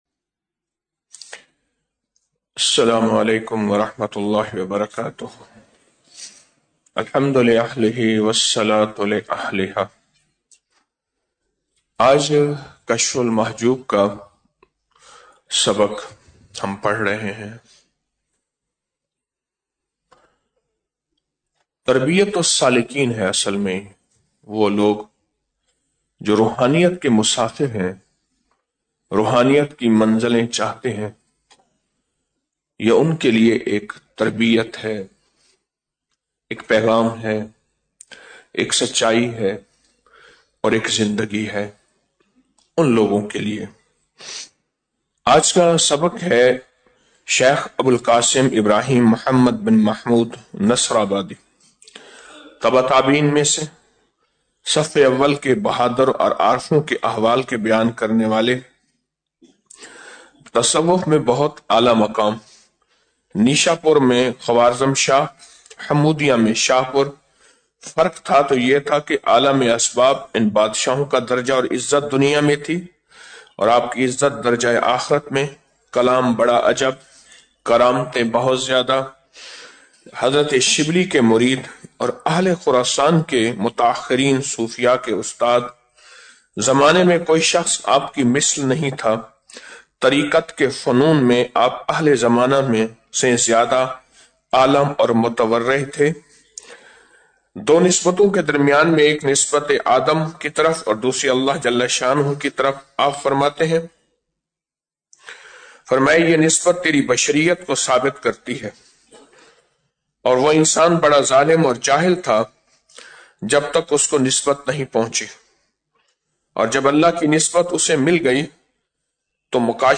Audio Speech - 05 Ramadan After Salat Ul Fajar - 06 March 2025